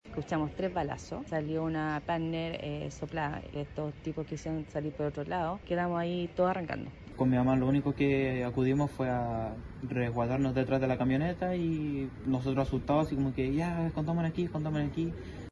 Una vecina del sector aseguró escuchar múltiples impactos de bala, por lo que buscó resguardo de inmediato en su inmueble.